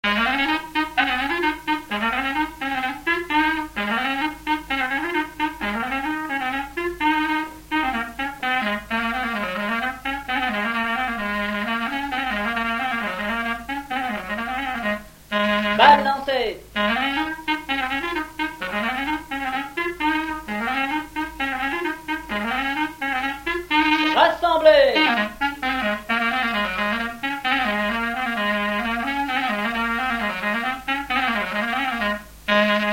Avant-deux
Résumé instrumental
danse : branle : avant-deux
Pièce musicale inédite